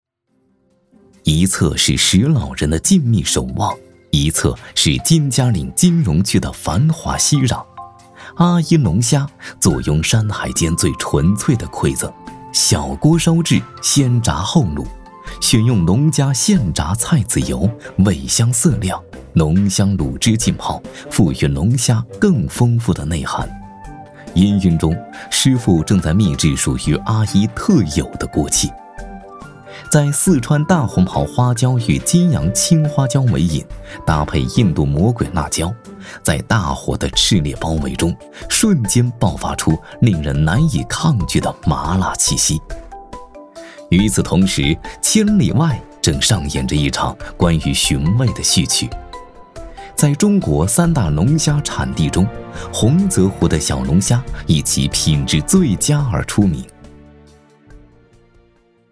男国语114